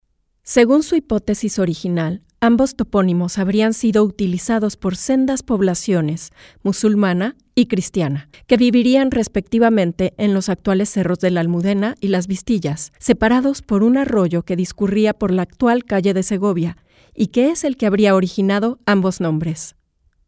Mexican voice over. Mexican voice over agency
Mexican female voices